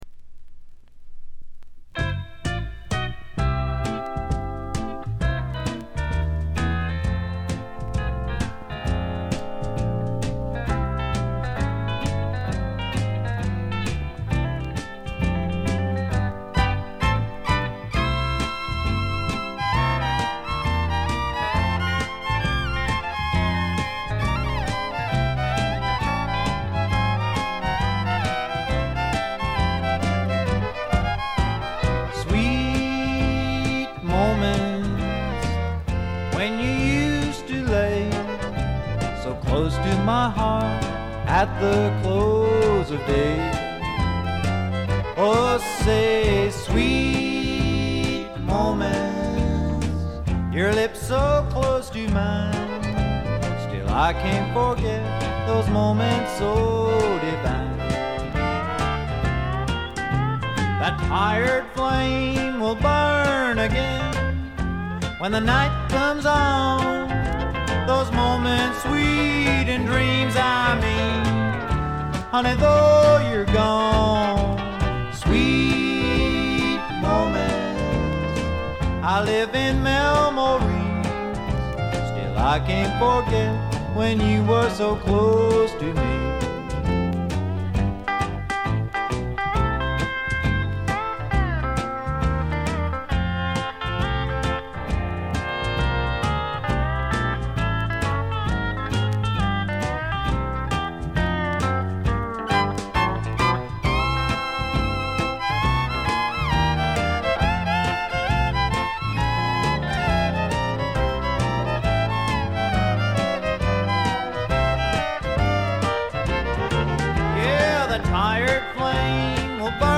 これ以外は軽微なチリプチ少々で良好に鑑賞できると思います。
試聴曲は現品からの取り込み音源です。